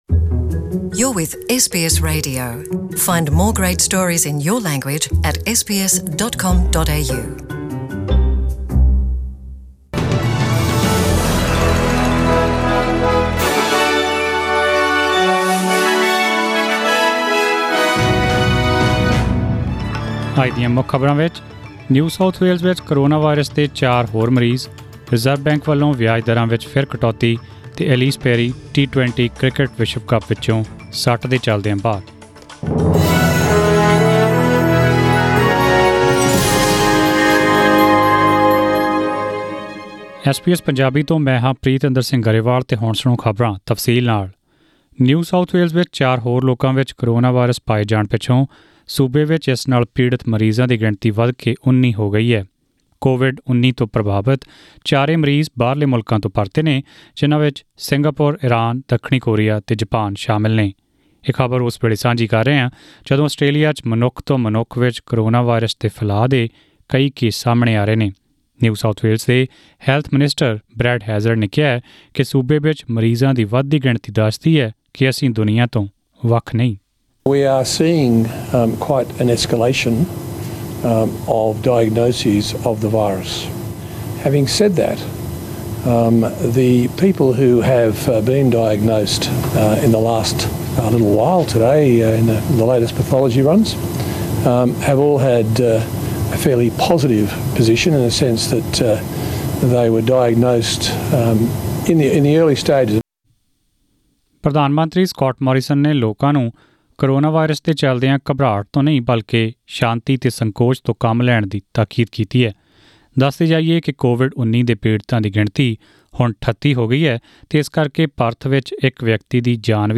In this bulletin -